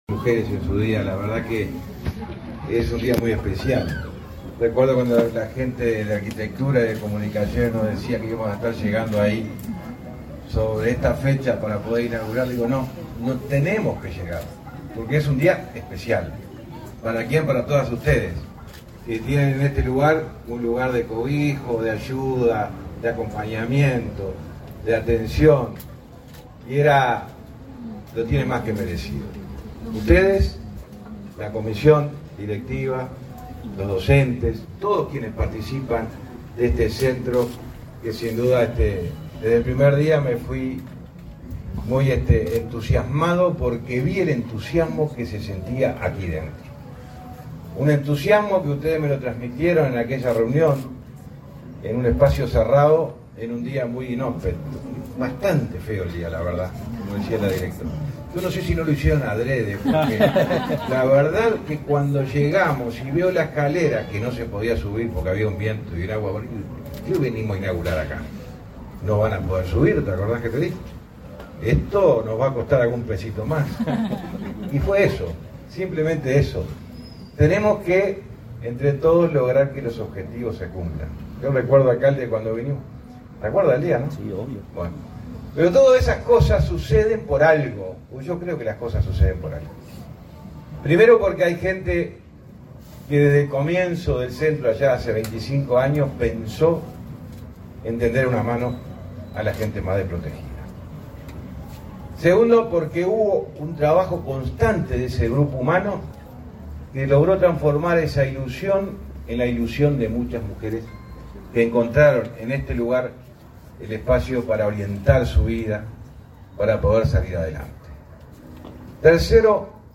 Conferencia de prensa por la inauguración de obras del convenio MTOP y Ceprodih
El Ministerio de Transporte y Obras Públicas (MTOP) y la organización social Centro de Promoción por la Dignidad Humana (Ceprodih) inauguraron, este 8 de marzo, obras realizadas por convenio entre ambas instituciones. Participaron en el evento el secretario de la Presidencia, Álvaro Delgado, y el ministro del MTOP, José Luis Falero.